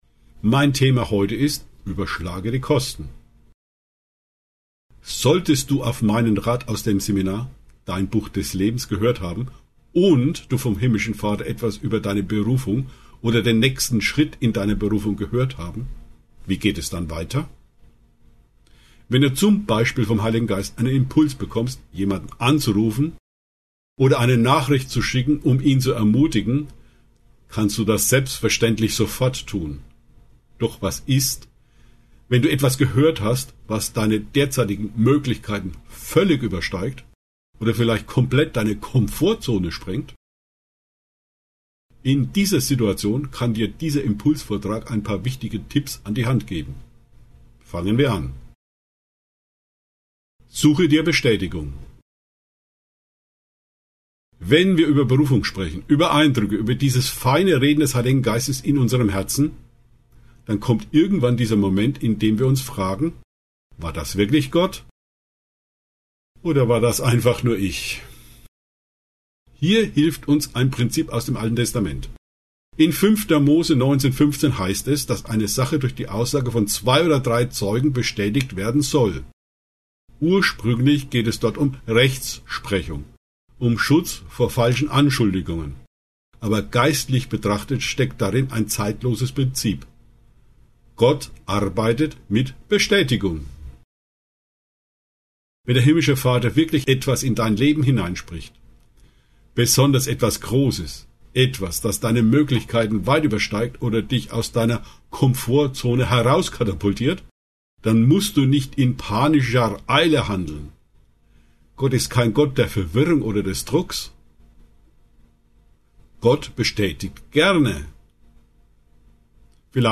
In diesem Impulsvortrag geht es um drei zentrale Fragen: Wie erkenne ich, ob es wirklich Gott ist?